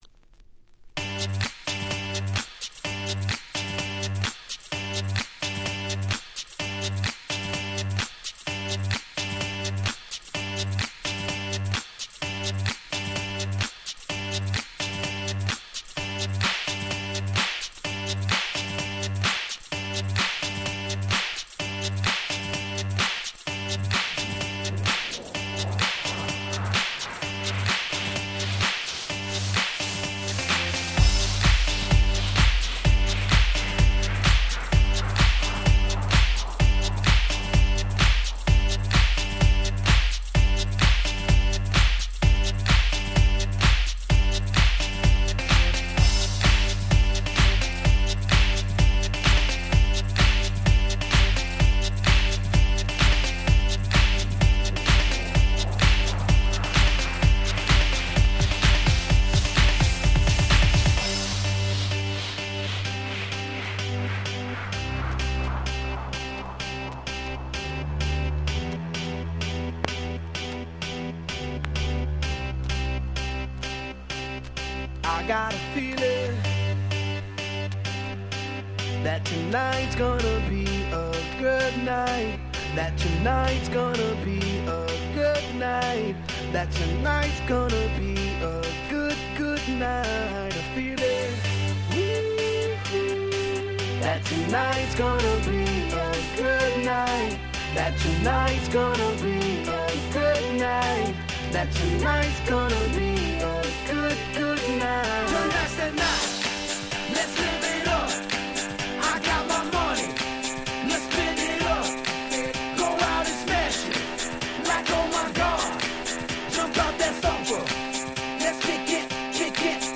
みんな大好き、超絶アゲアゲクラシック！
EDM アゲアゲ パリピ キャッチー系